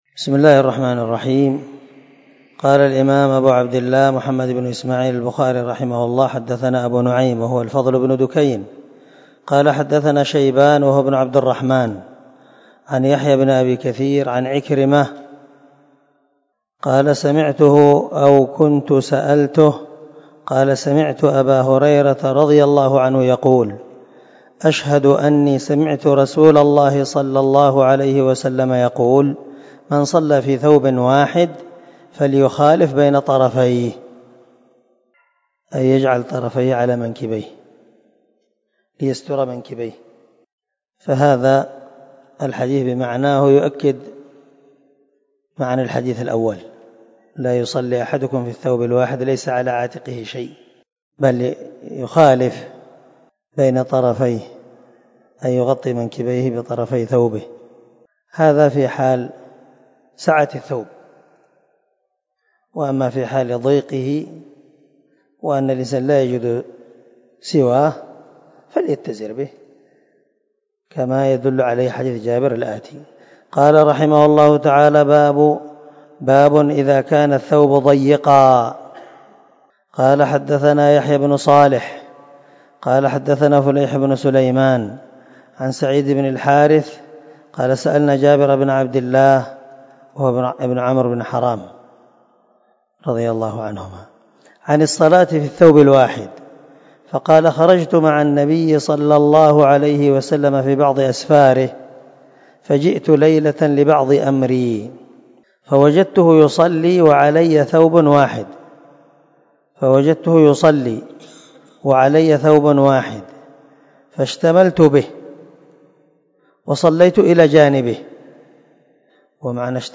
275الدرس 8 من شرح كتاب الصلاة حديث رقم ( 360 - 361 ) من صحيح البخاري